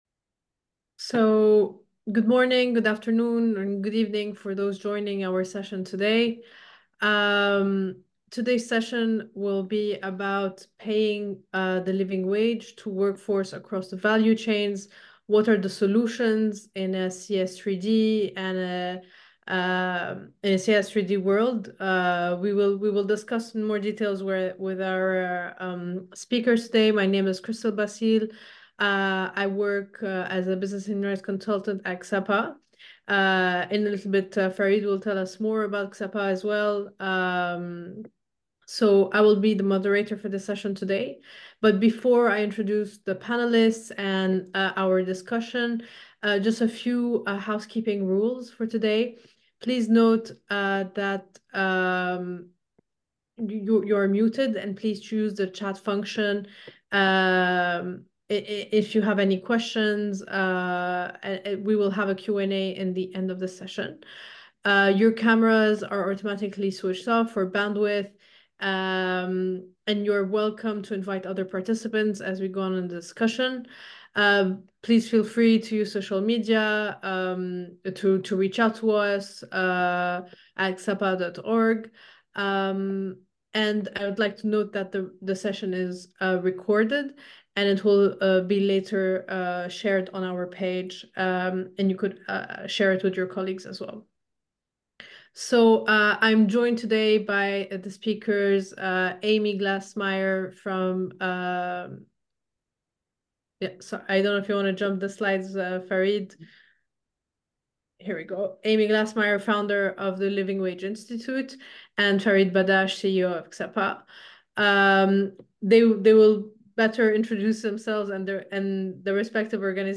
Session enregistrée le 14 mai 2024 (en anglais)